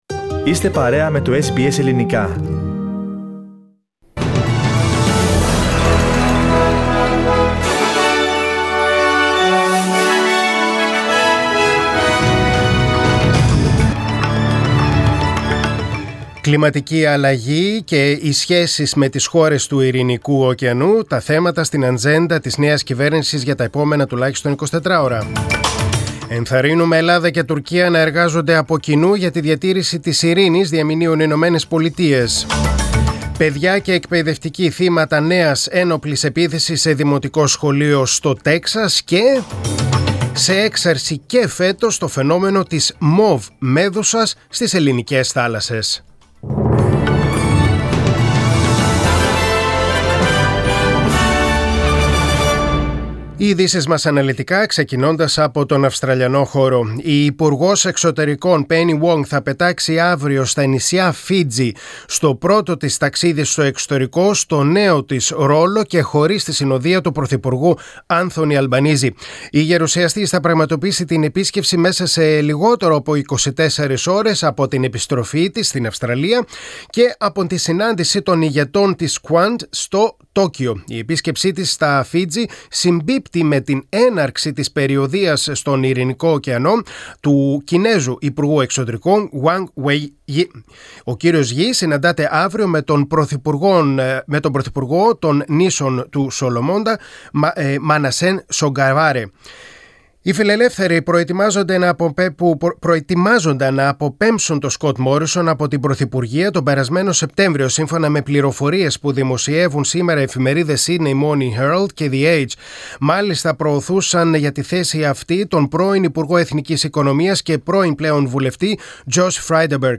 Δελτίο Ειδήσεων: Τετάρτη 25.5.2022